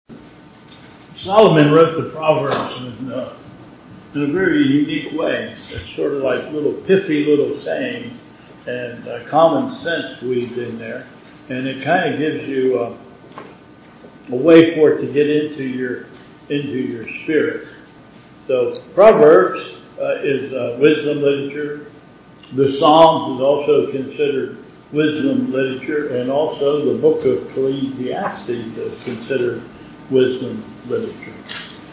Bethel Church Service